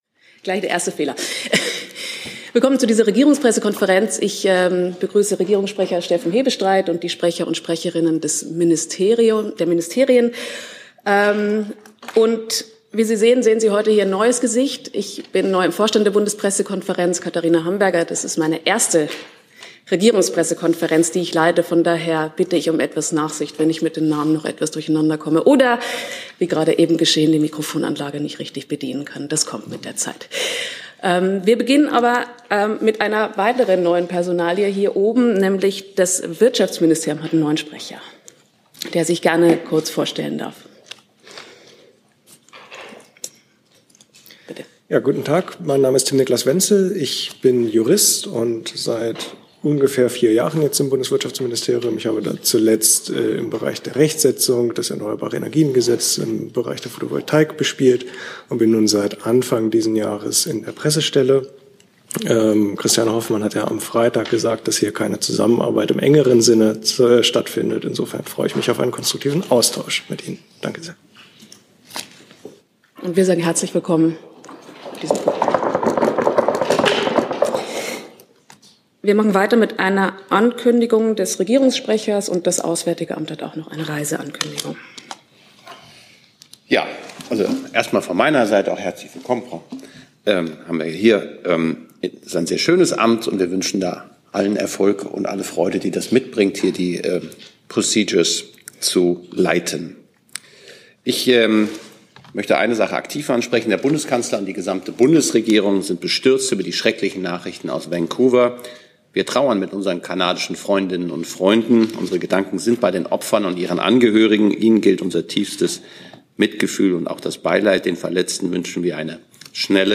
Komplette Regierungspressekonferenzen (RegPK) und andere Pressekonferenzen (BPK) aus dem Saal der Bundespressekonferenz.